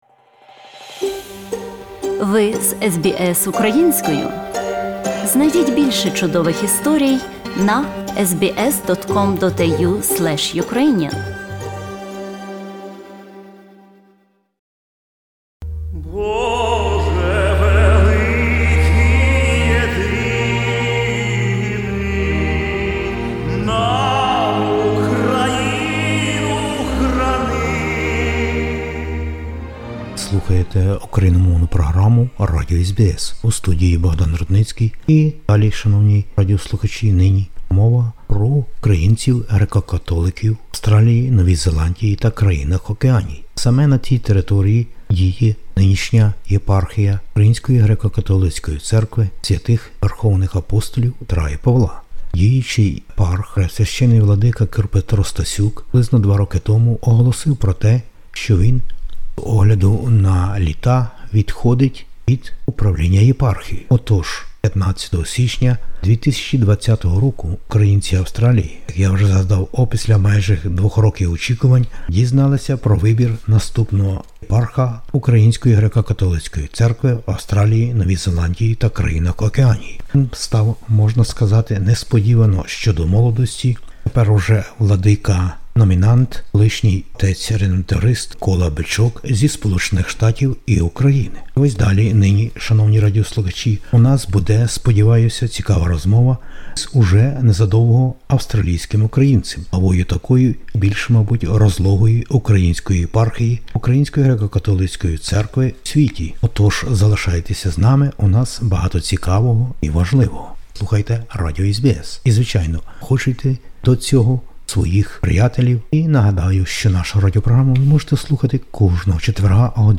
SBS Ukrainian інтерв'ю. Це сталося 15 січня 2020 року у Ватикані. Св. Отець Папа Франциск задовільнив вибір Синоду УГКЦ і поблагословив нового главу Мельбурнської єпархії Свв. Верх. Апп. Петра і Павла УГКЦ в Австралії, Новій Зеландії та країнах Океанії.